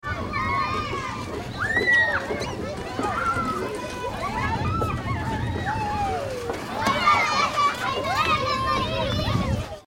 GARDENKIDS AMB KINDER
Ambient sound effects
Gardenkids_AMB_kinder.mp3